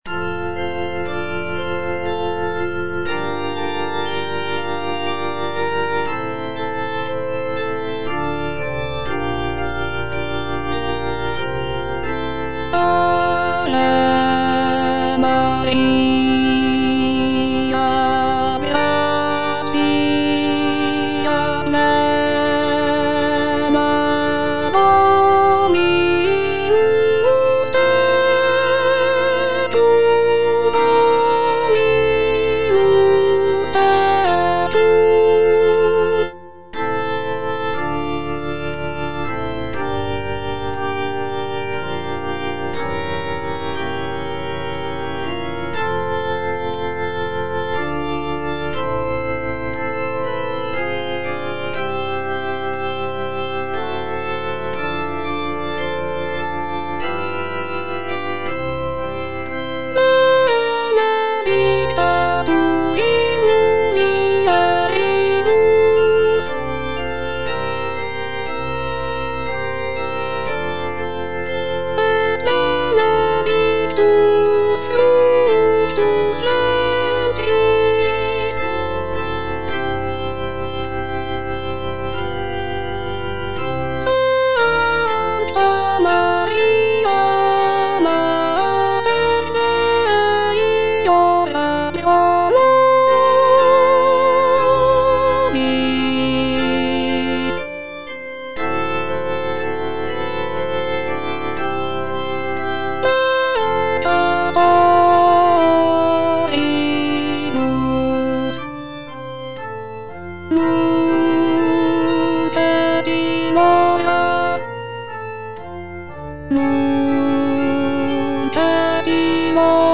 Alto (Voice with metronome)